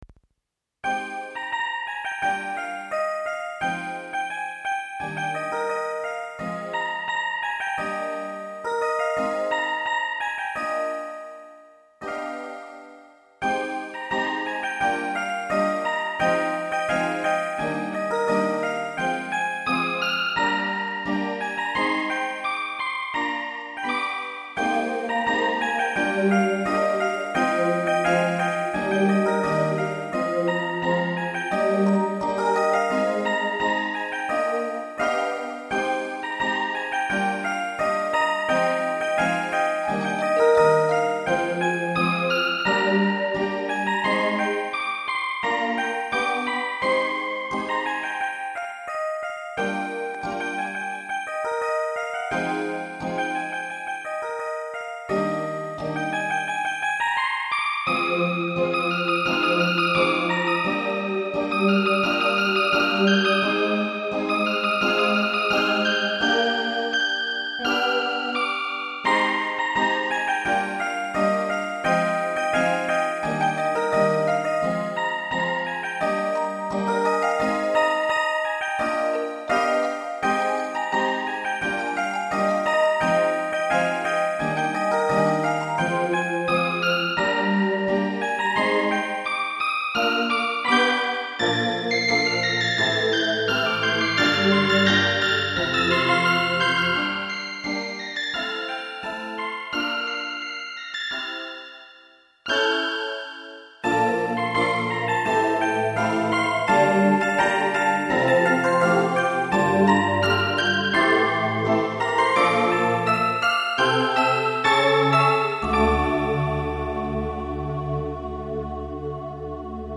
大変シンプルで静かな曲。